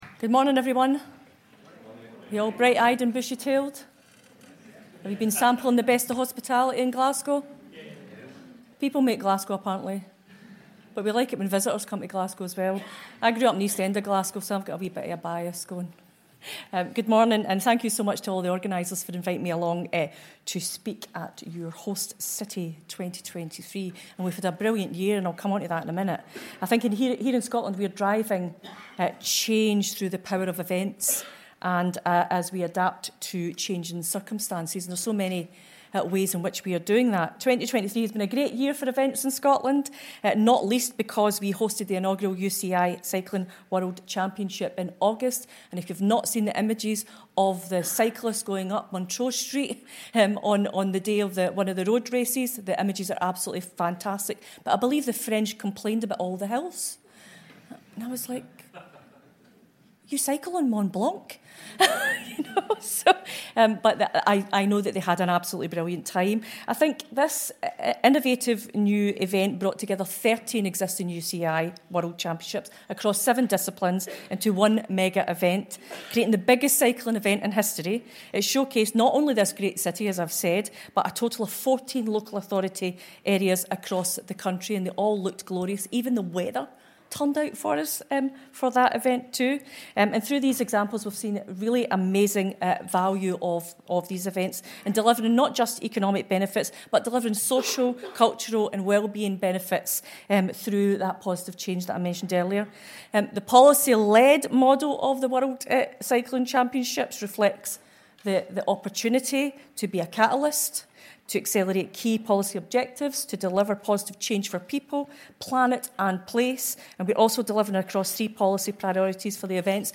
Keynote Address
Christina McKelvie MSP, Minister for Culture, Europe and International Development, Scotland